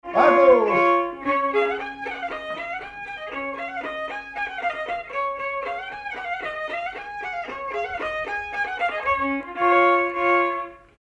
danse : mazurka
circonstance : bal, dancerie
Pièce musicale inédite